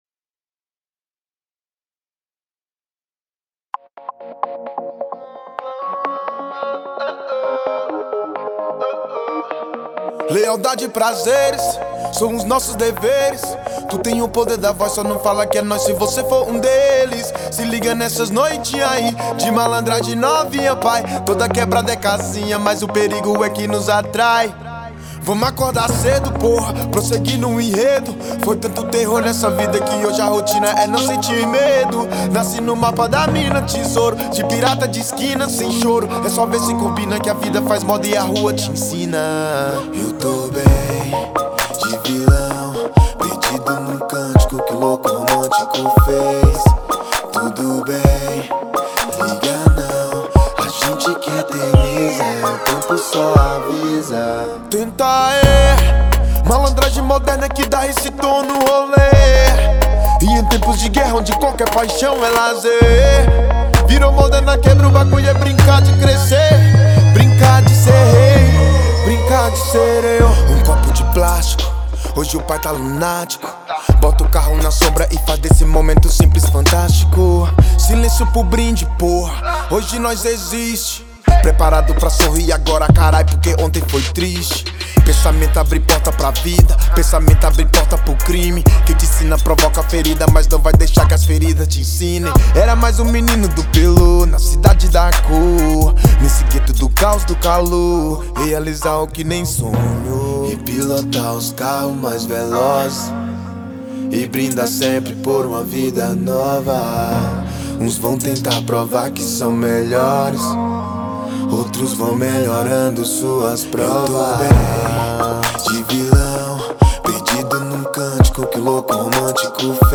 2025-03-17 19:34:08 Gênero: Rap Views